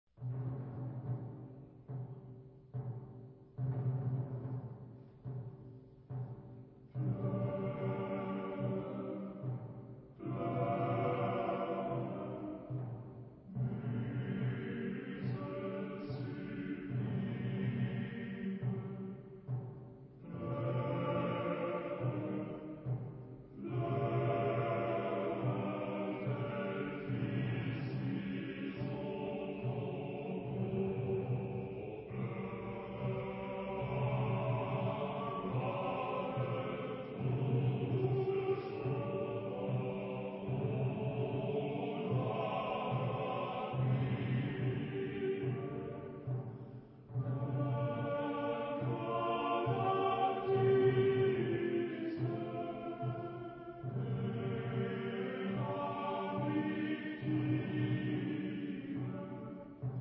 Genre-Stil-Form: romantisch ; weltlich
Chorgattung: TTBB  (4 Männerchor Stimmen )
Instrumente: Percussion
Tonart(en): G-Dur ; g-moll